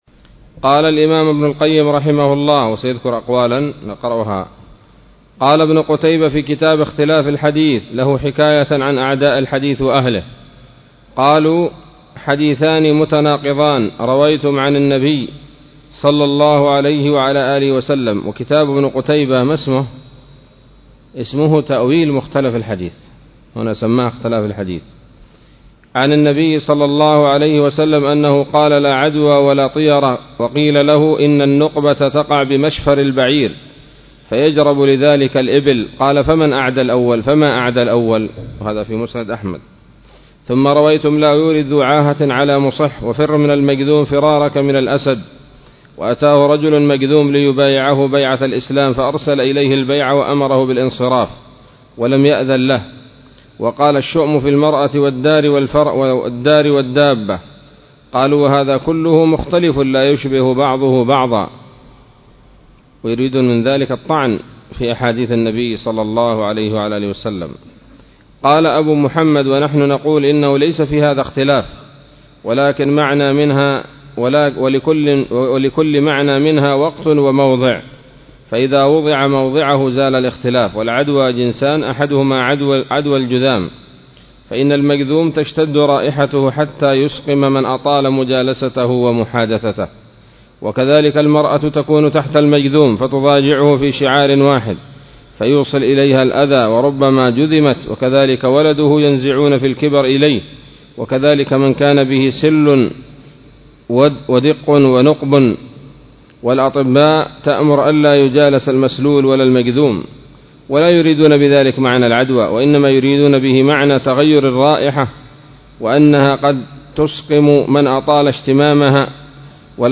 الدرس الثاني والأربعون من كتاب الطب النبوي لابن القيم